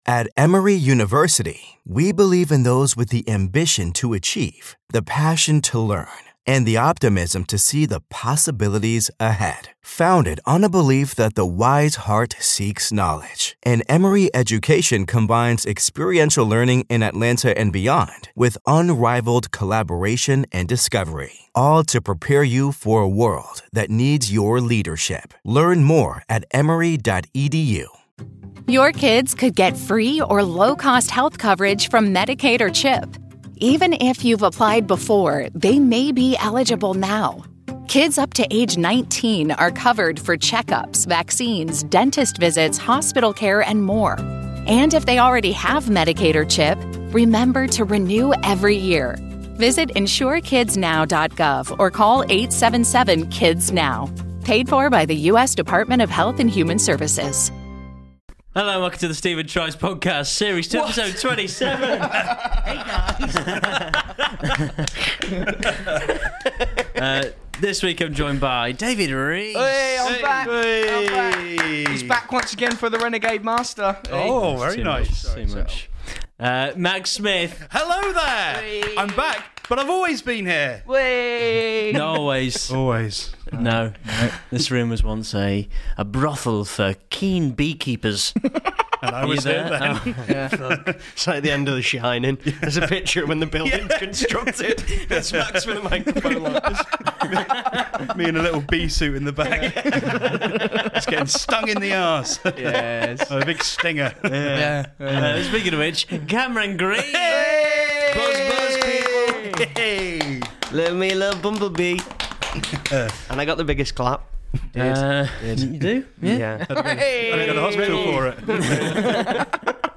This week four beta males talk about everything from the Middle East to Ryan Reynolds.